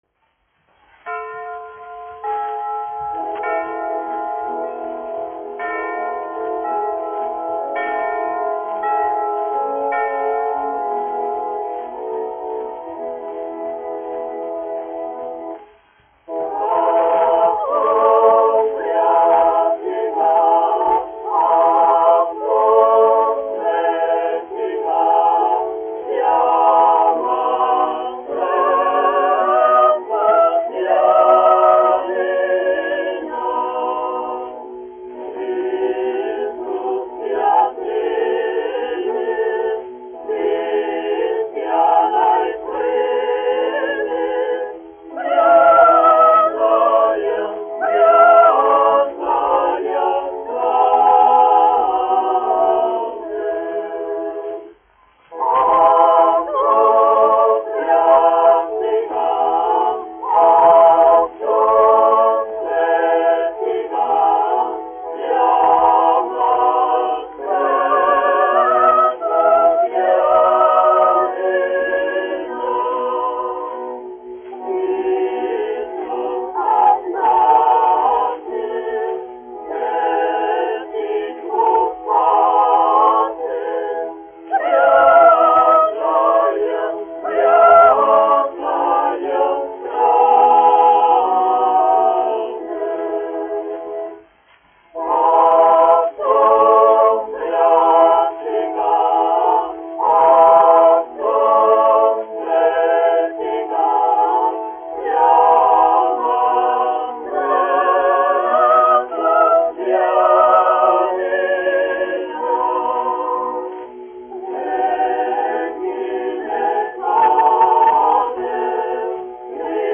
Rīgas Latviešu dziedāšanas biedrības jauktais koris, izpildītājs
1 skpl. : analogs, 78 apgr/min, mono ; 25 cm
Ziemassvētku mūzika
Skaņuplate